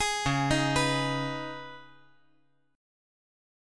Listen to C#7sus2 strummed